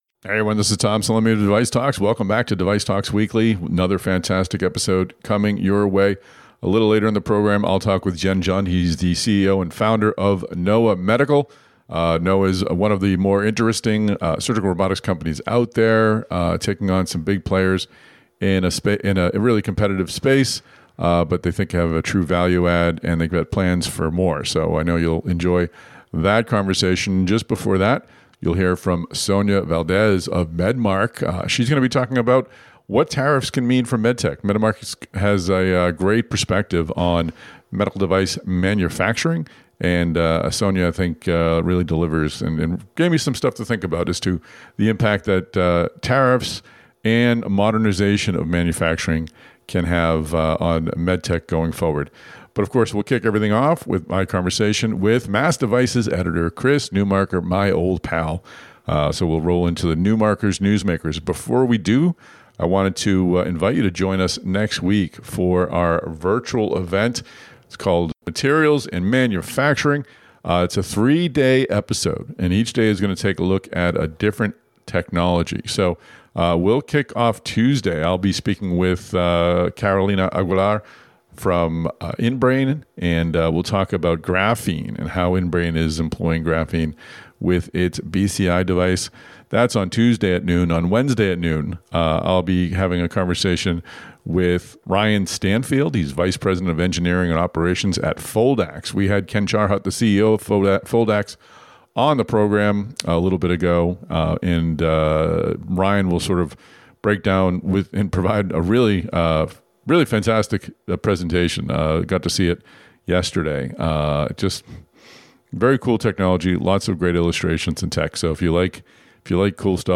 (Note: This interview was recorded before ZB’s announced acq…